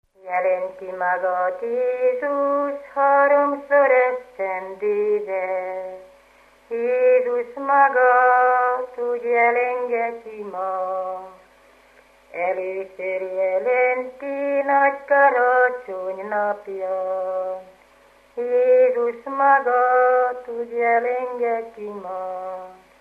Felföld - Nyitra vm. - Ghymes
Stílus: 7. Régies kisambitusú dallamok
Kadencia: 5 (2) 1